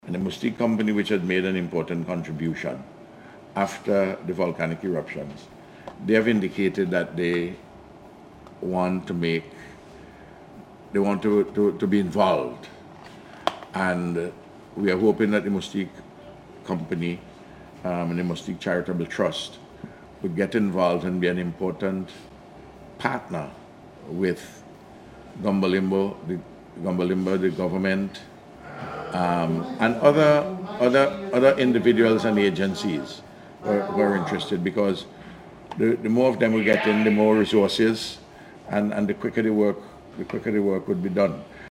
The Prime Minister was speaking at a handing over of heavy construction equipment and materials on Saturday.